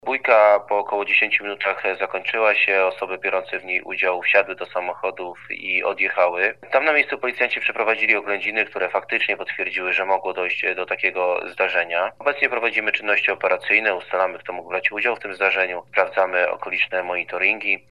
Pliszczyn-komentarz.mp3